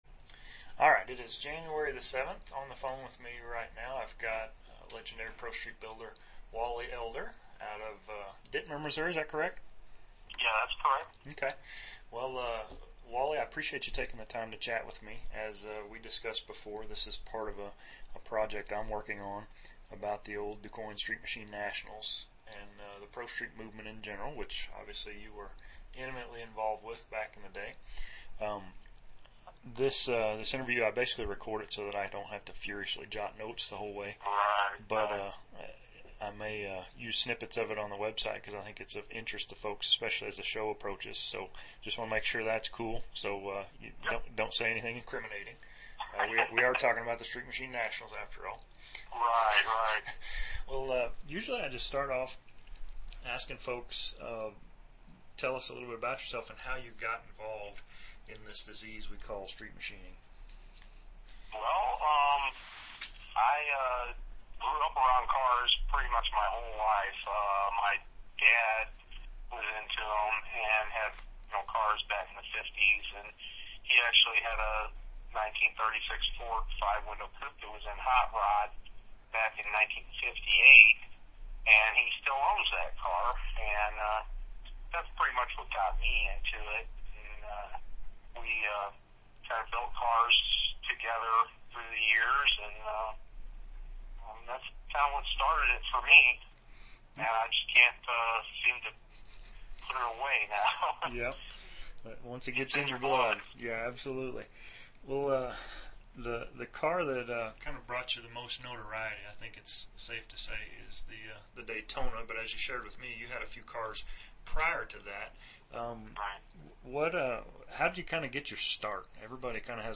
Well, it’s a good thing I don’t build cars like I do interviews!